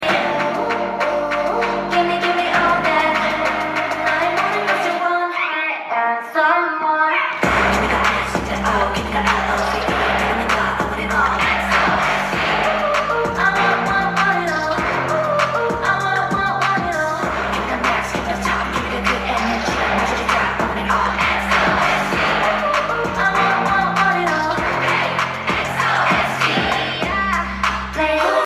Fancam
kpop